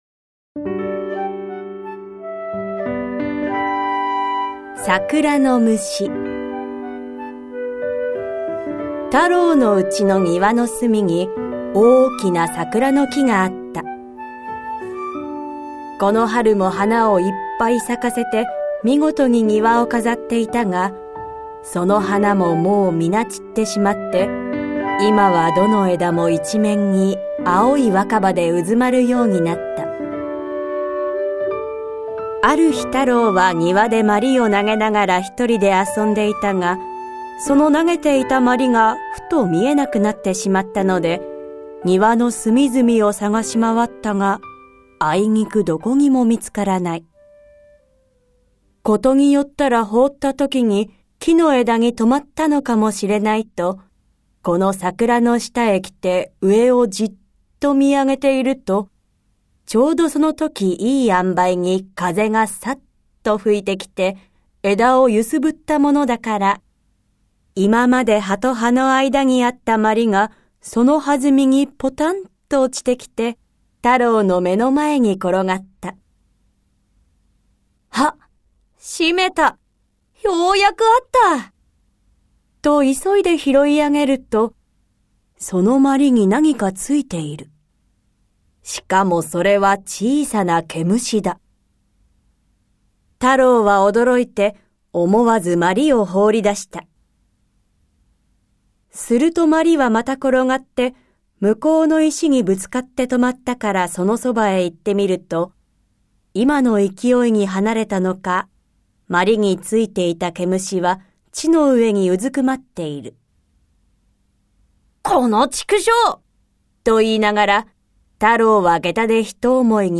[オーディオブック] 巌谷小波童話集 短話編 2(全43話収録)
子どもの想像力を豊かに育む 昔話とファンタジーの読み聞かせオーディオブック
プロとして活躍する朗読家や声優、ナレーター達が感情豊かに読み上げます。